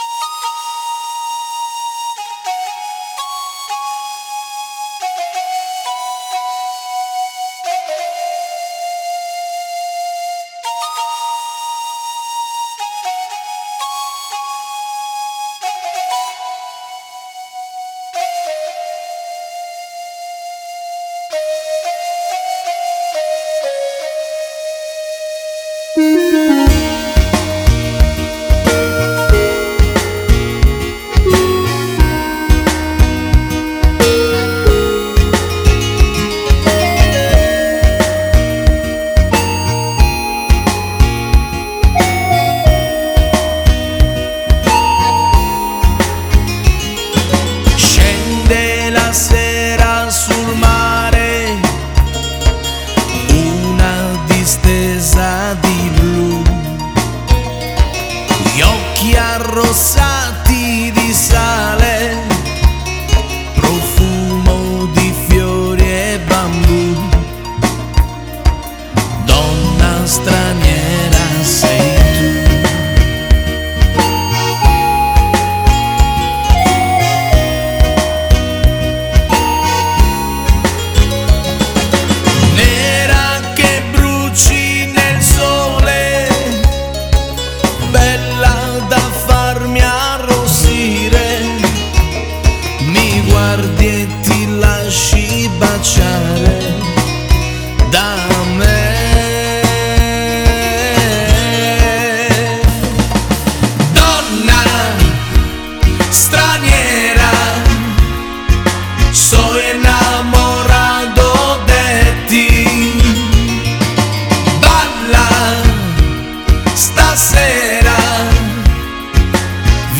Kizomba